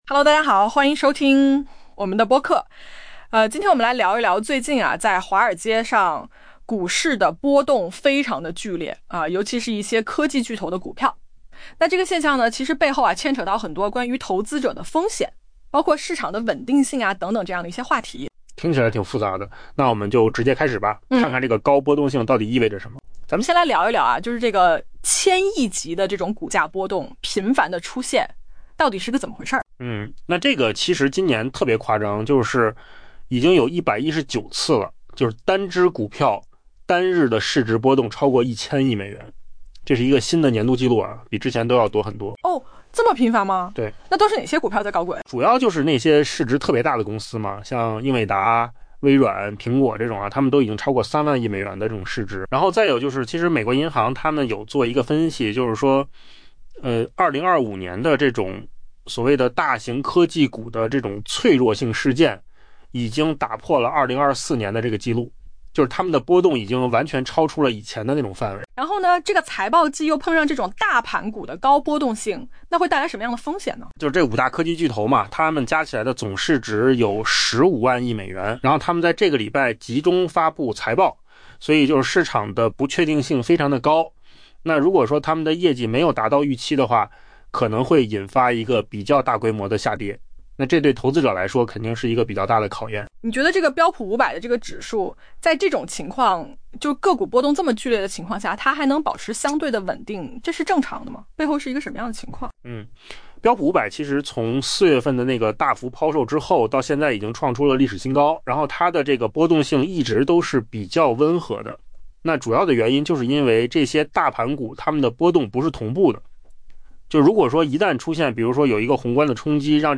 AI 播客：换个方式听新闻 下载 mp3 音频由扣子空间生成 华尔街单日股价波动规模达数千亿美元已成为常态。